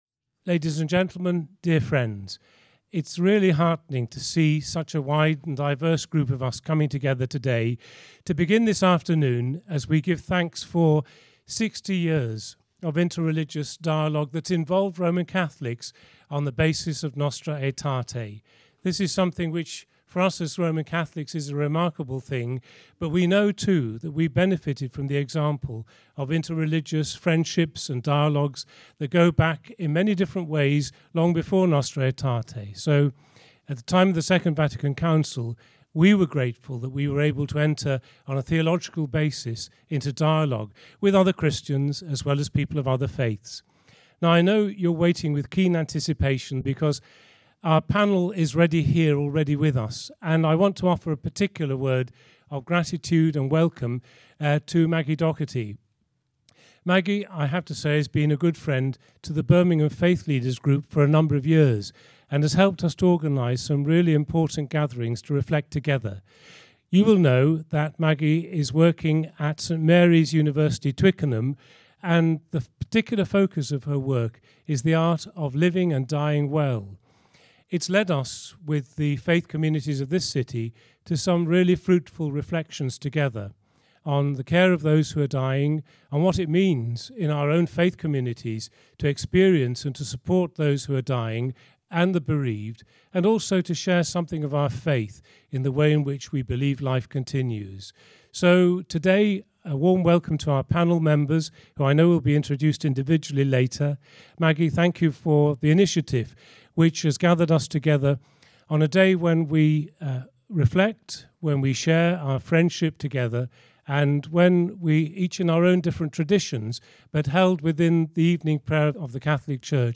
Interfaith panel discussion on end-of-life care